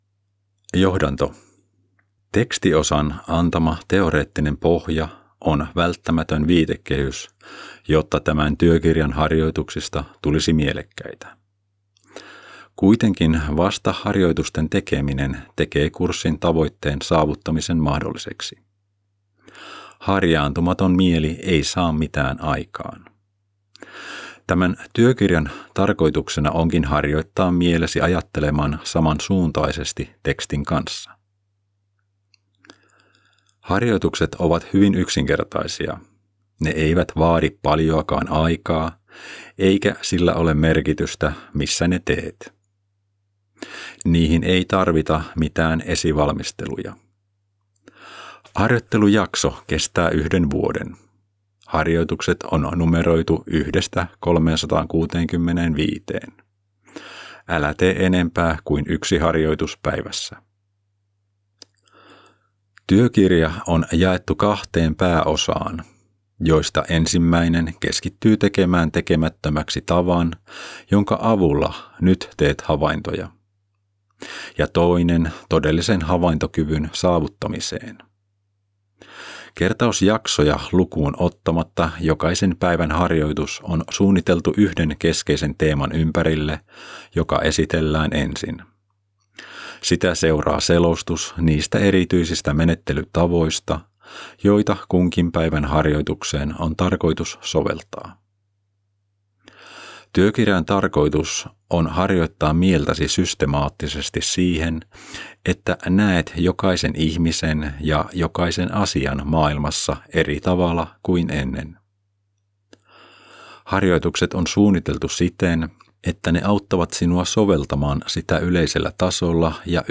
Announcing the Finnish audiobook!
recorded in Finland